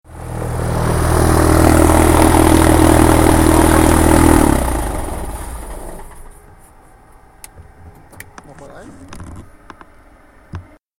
Es gibt satten Sound, reichlich Power und entspanntes Fliegen!
Und deshalb gibt es auch nicht dieses markerschütternde Geräusch beim Abstellen des Triebwerks….
Soundcheck
ULPower260IAusschalten.mp3